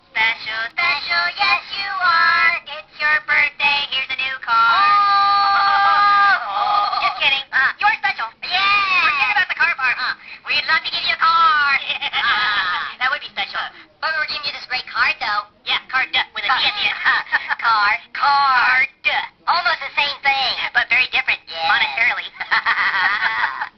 Sooooo Special! is a hoops&yoyo greeting card with sound made for birthdays.
Card sound